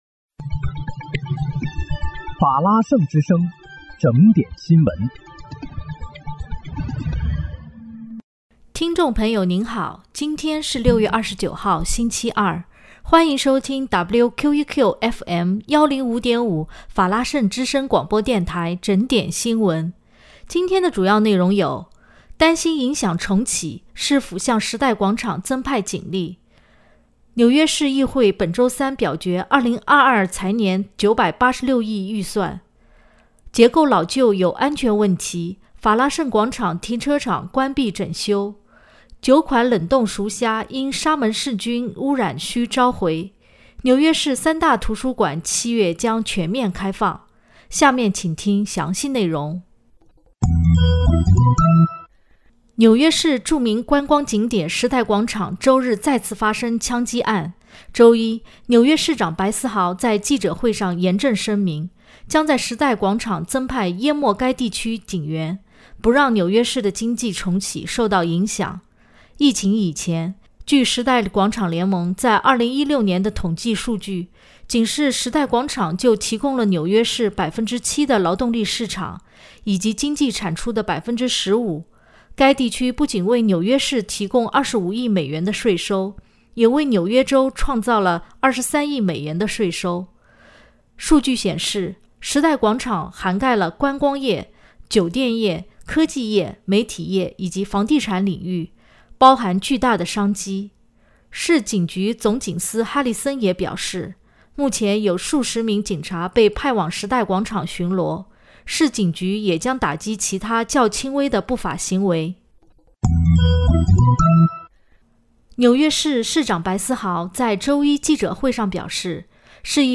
6月29日（星期二）纽约整点新闻